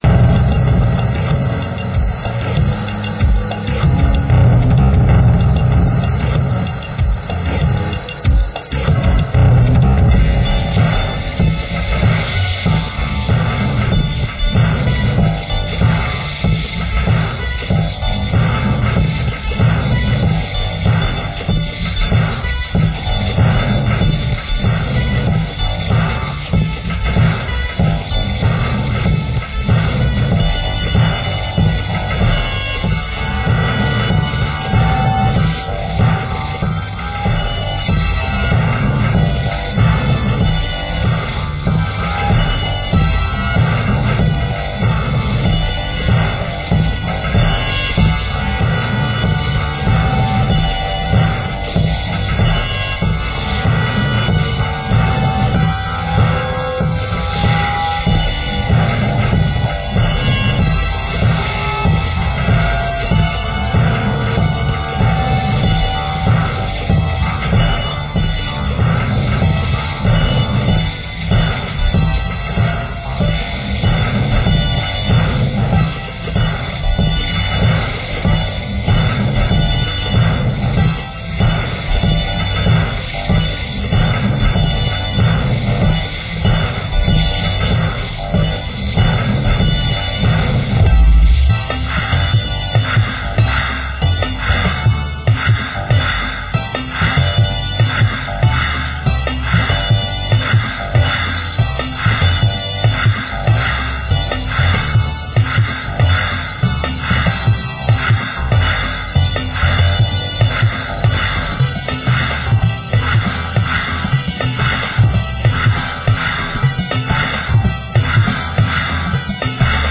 Royalty Free Music for use in any type of
Heavy backing beat with some bell like percussion
and deep growling basses.